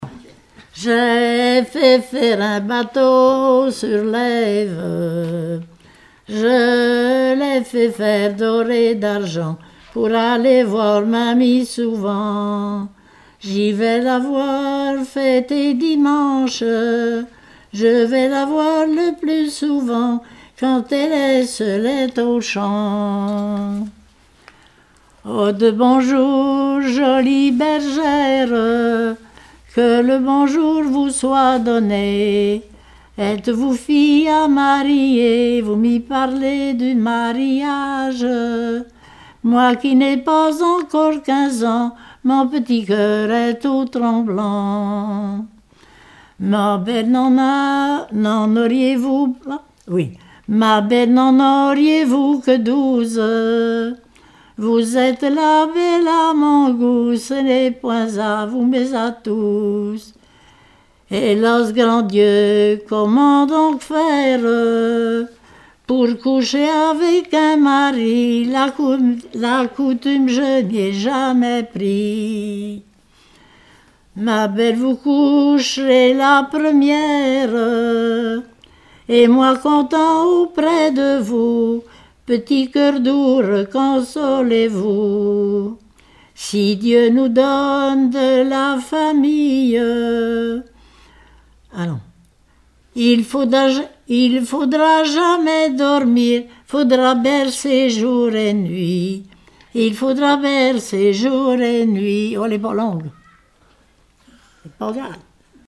Genre strophique
Collectif-veillée (2ème prise de son)
Pièce musicale inédite